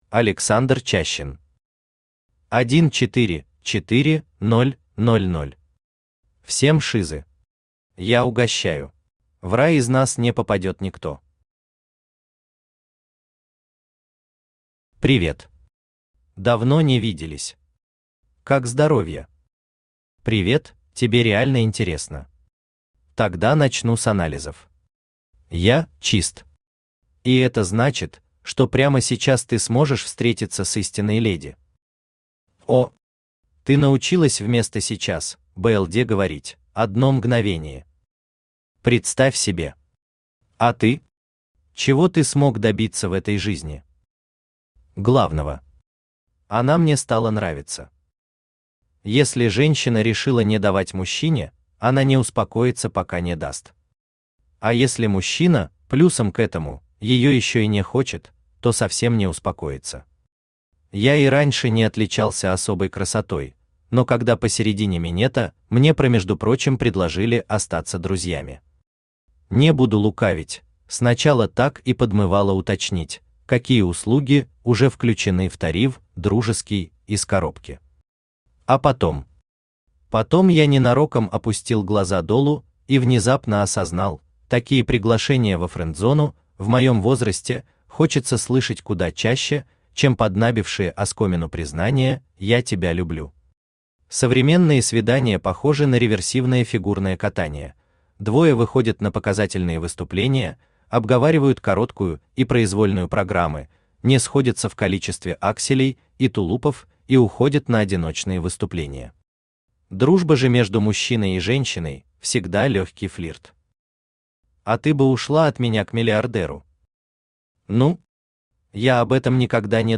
Автор Александр Чащин Читает аудиокнигу Авточтец ЛитРес.